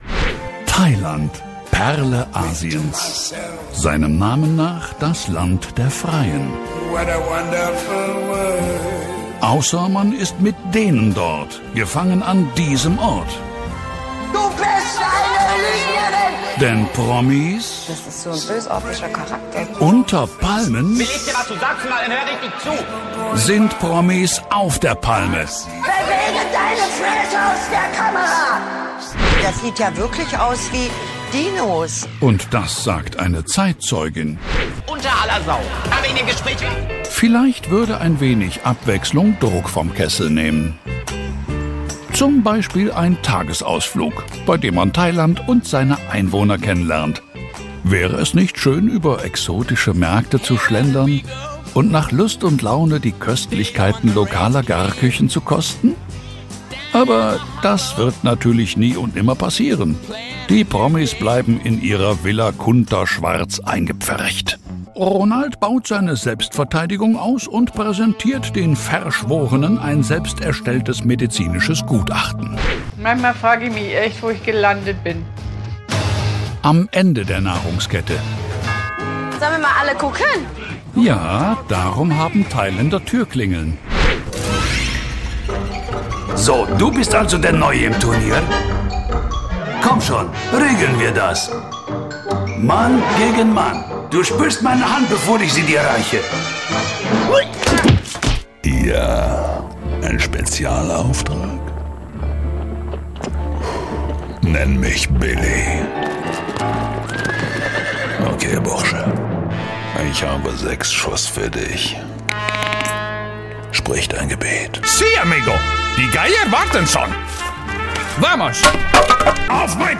Experienced (30+ years) versatile German voice actor
Voice Reel: Voice of God | Celebrity Reality TV Show | Games | Trailers | Audio Drama (Dialects & Characters) – German
In addition to a clear, neutral native German (Hochdeutsch – Bühnendeutsch), I am fluent in a wide range of regional German dialects and international accents, allowing me to deliver authentic and versatile voice performances.
I record from my fully equipped studio, delivering high-quality audio with a fast turnaround–depending on project scope and availability–to help you communicate your message effectively and connect authentically with your audience.
Voice Reel - Voice of God | Reality TV | Games | Trailers | Audio Drama (Dialects & Characters).mp3